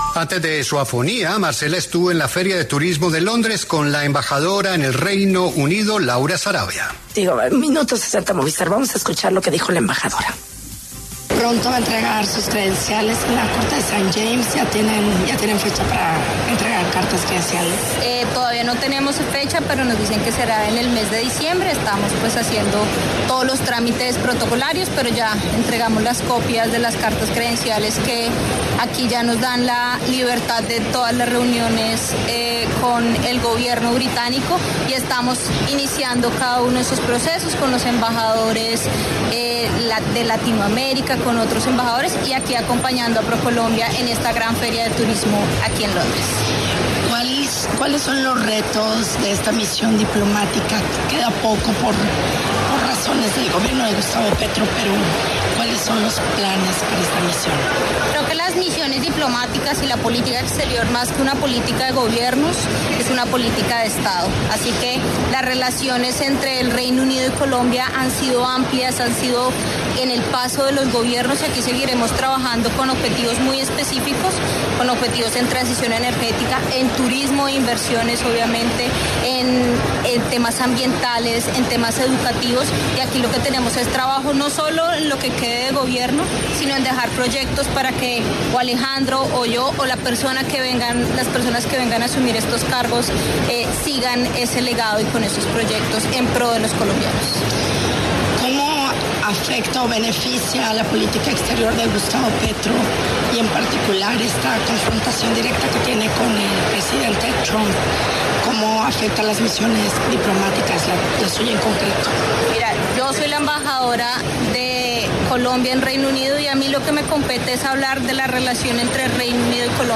La embajadora de Colombia en Reino Unido, Laura Sarabia, conversó con La W sobre la apertura del pabellón Colombia en el World Travel Market (WTM) Londres 2025.
Desde la inauguración del pabellón de Colombia en el World Travel Market (WTM) Londres 2025, organizado por Procolombia, la embajadora del país en Reino Unido, Laura Sarabia, pasó por los micrófonos de La W para hablar sobre sus tareas en este nuevo cargo.